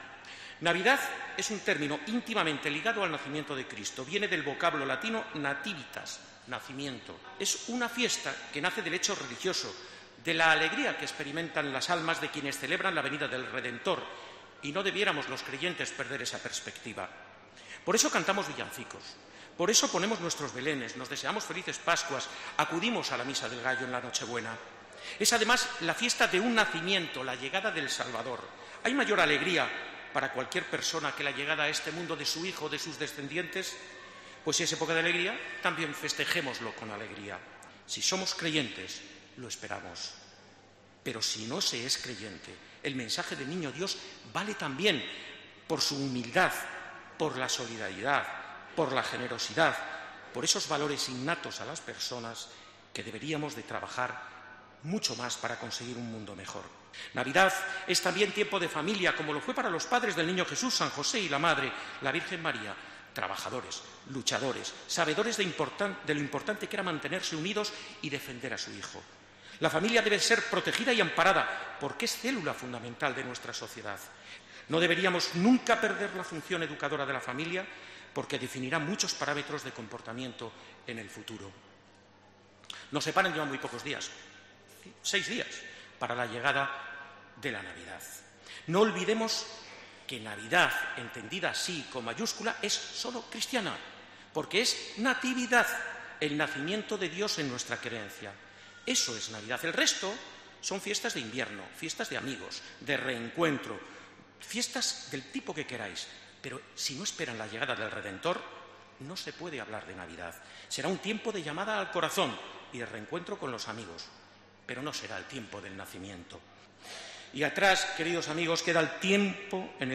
Ante el Belén monumental de la Iglesia Nuestra Señora de San Lorenzo reivindica la humildad, la solidaridad y la unidad de la familia